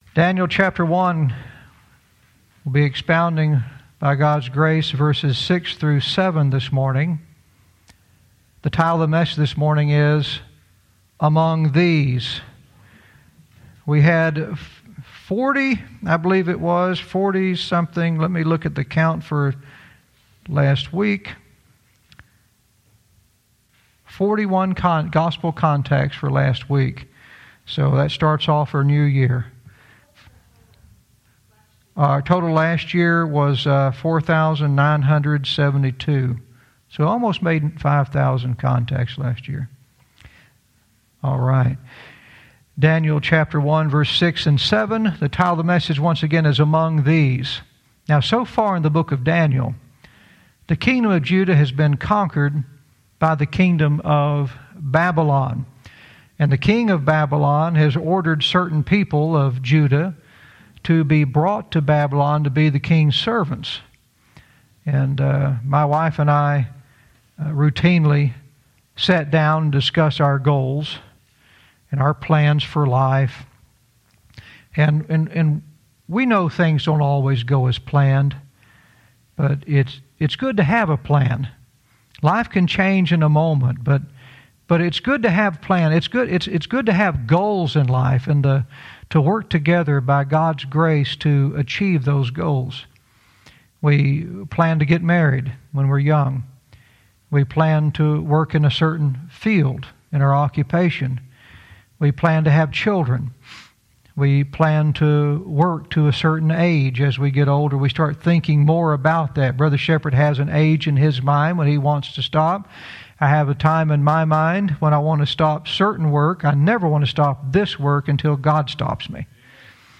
Verse by verse teaching - Daniel 1:6-7 "Among These"